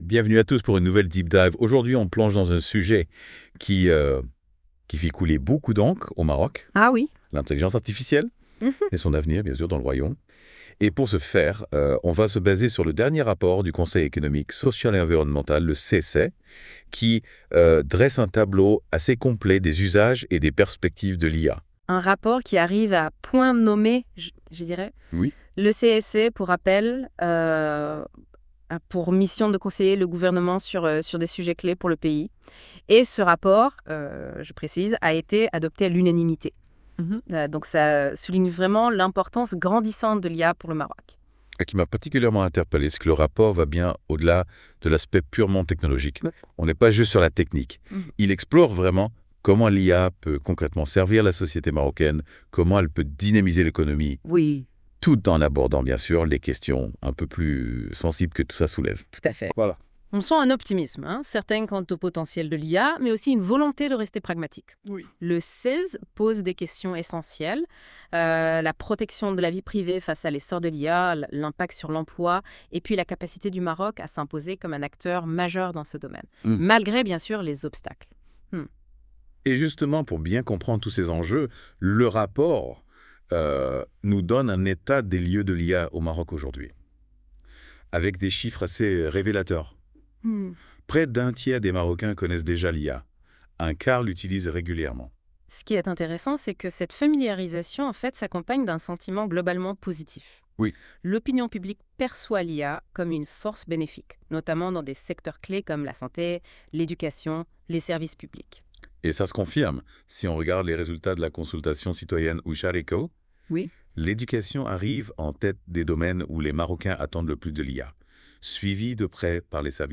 Débat des chroniqueurs de la Web Radio R212 sur un rapport du Conseil Économique, Social et Environnemental (CESE) du Maroc sur le développement de l'intelligence artificielle (IA) au Maroc.